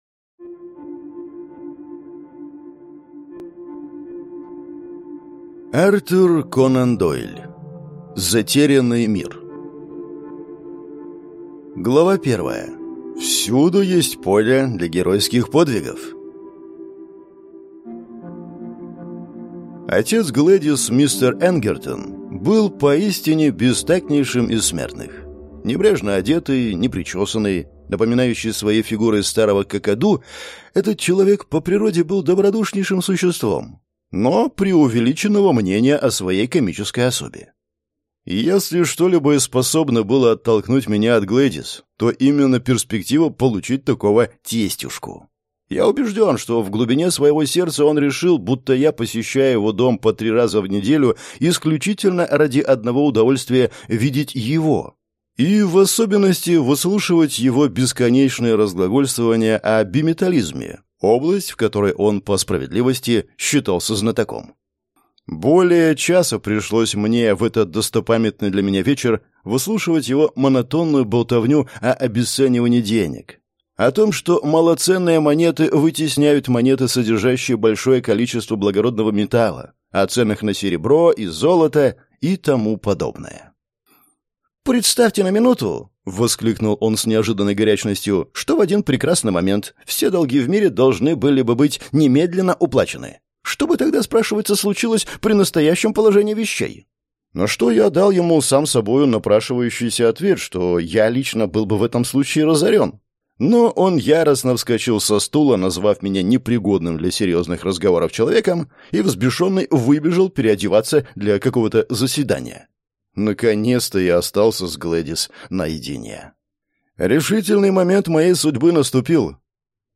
Аудиокнига Затерянный мир | Библиотека аудиокниг